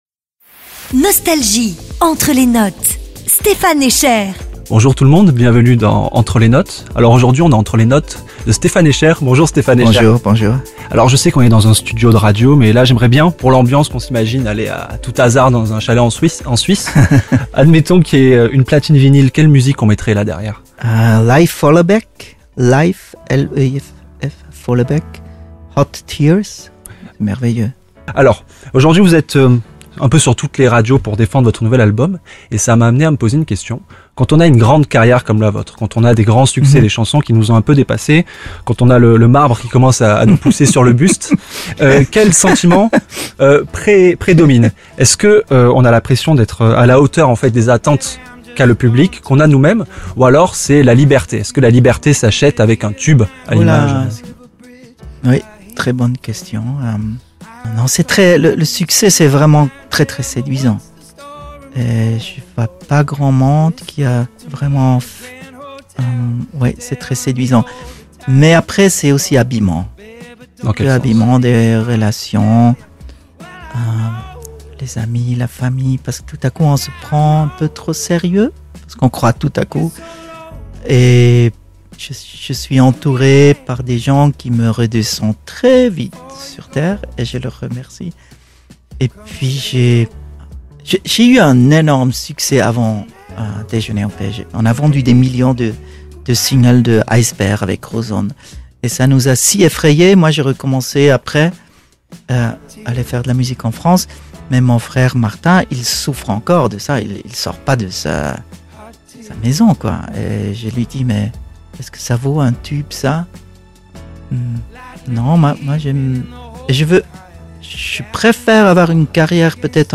Les Plus Grands Artistes Sont En Interview Sur Nostalgie.
revivez l'interview de michel polnareff à las vegas à l'occasion de la sortie de son nouvel album "enfin"